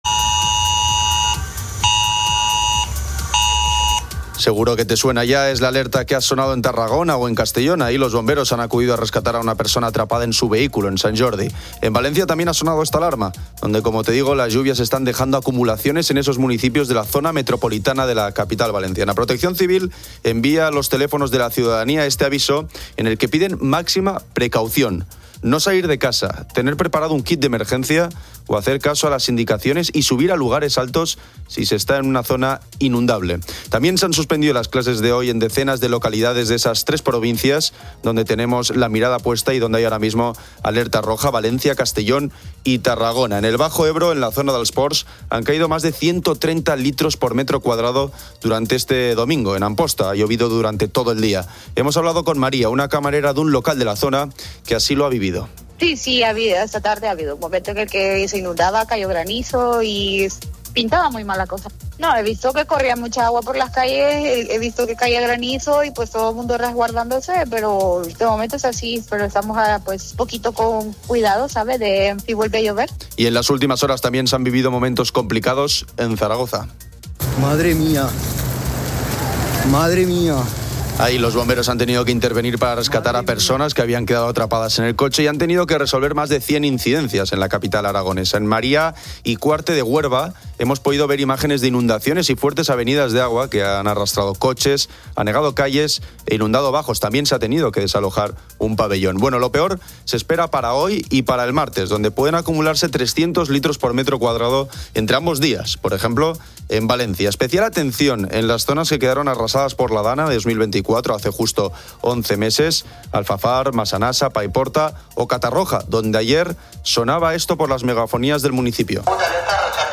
La audiencia de COPE comparte sus experiencias.